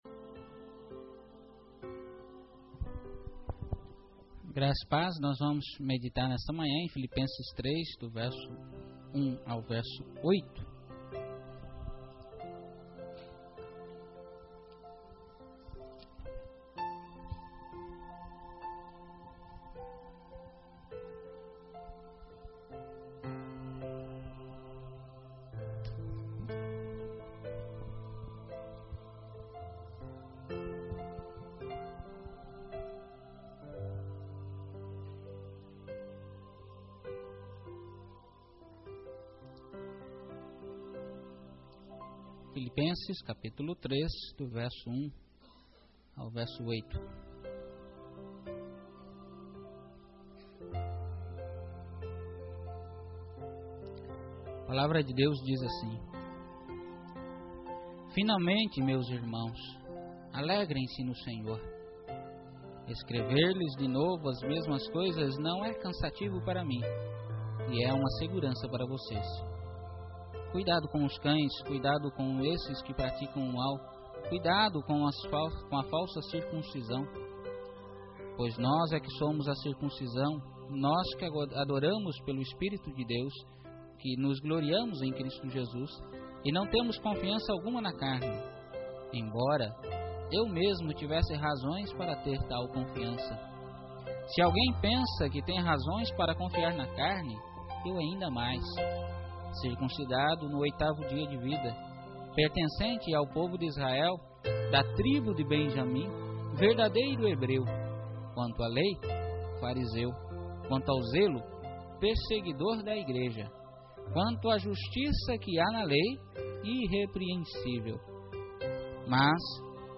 Pregacao quando Deus Restaura Paulo